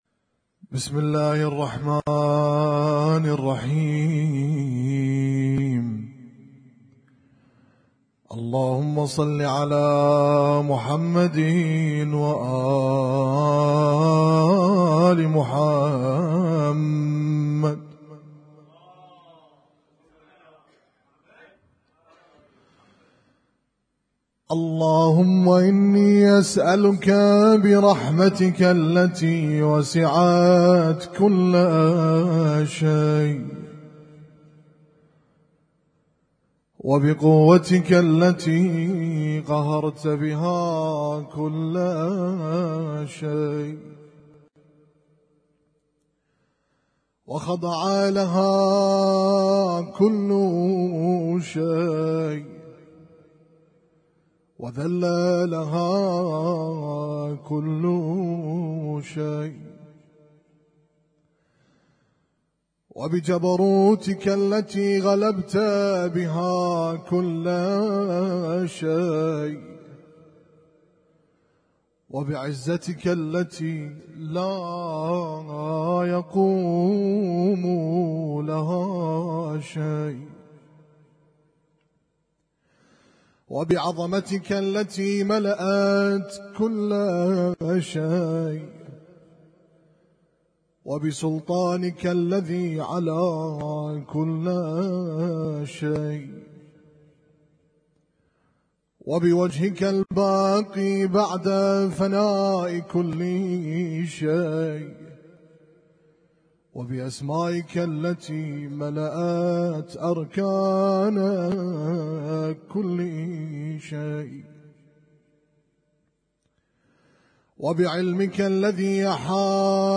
اسم التصنيف: المـكتبة الصــوتيه >> الادعية >> دعاء كميل
البث المباشر